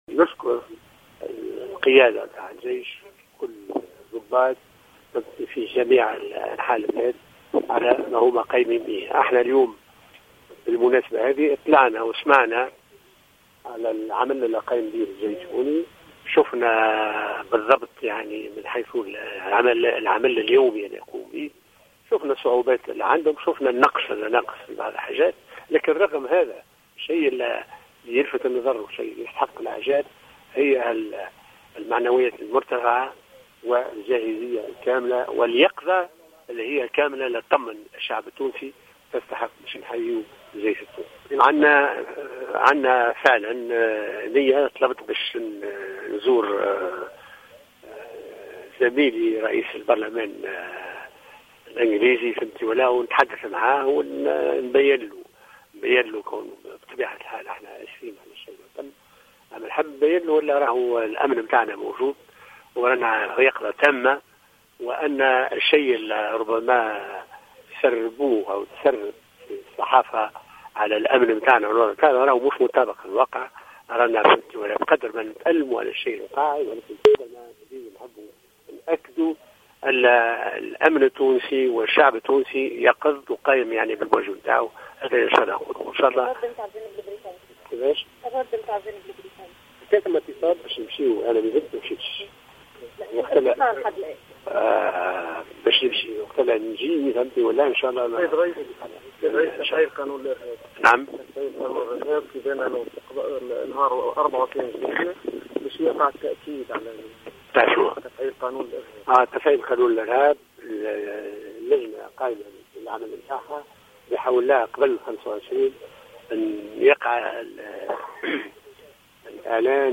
وأكد الناصر في تصريح للجوهرة أف أم أنه طلب من بريطانيا تمكينه من لقاء نظيره البريطاني للتحادث حول تداعيات الهجوم الإرهابي الذي جد في سوسة، وليطلعه على حسن استعداد الأجهزة الأمنية التونسية للتصدي للمخاطر الإرهابية على عكس ما تروج له عديد وسائل الإعلام حول عجزها عن مكافحة الإرهاب.